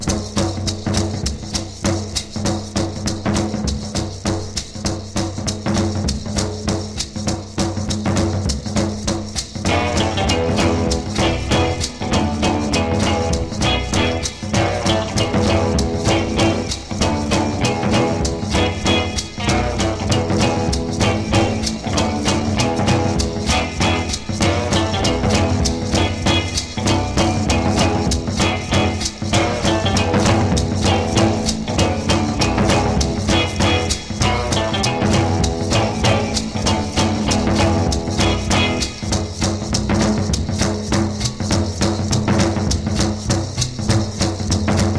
Rock'N'Roll, Jazz　USA　12inchレコード　33rpm　Mono